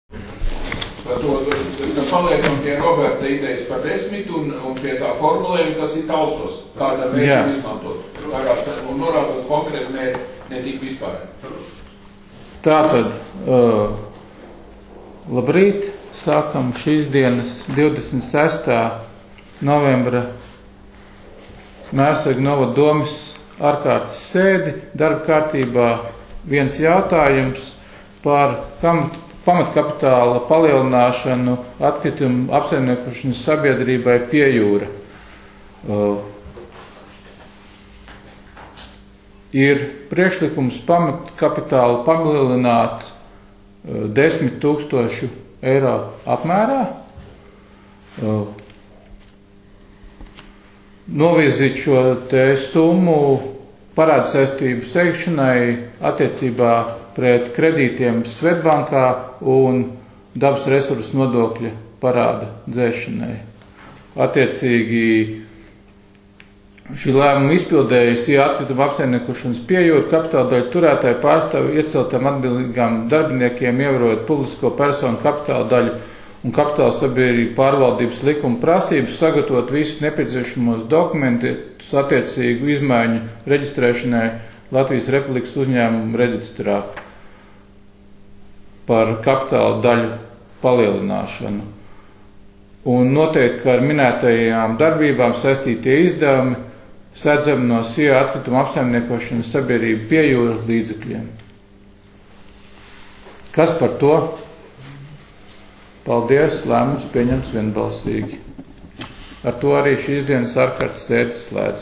Mērsraga novada domes sēde 27.11.2020.